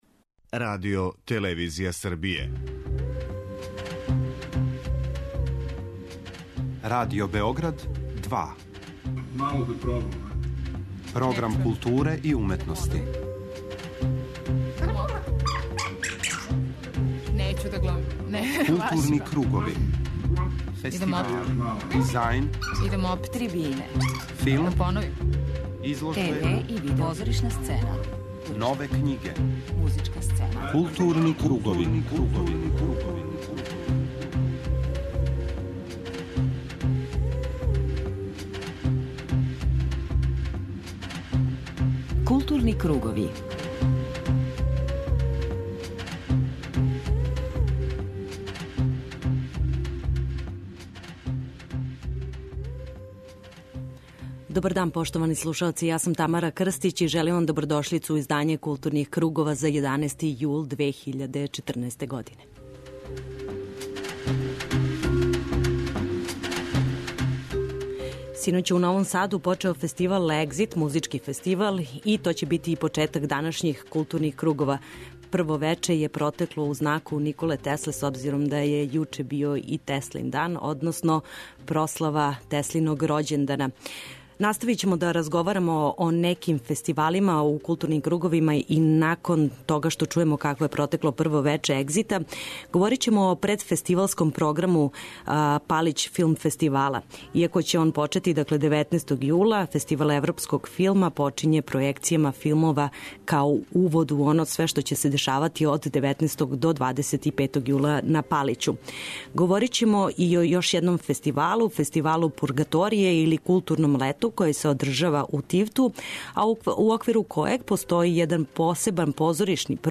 У првом сату извештавамо вас о културним актуелностима код нас, а у тематском делу емисије, 'Гутенберговом одговору', овога петка слушаћете разговоре са добитницима књижевних награда 'Васко Попа' и 'Исидора Секулић'.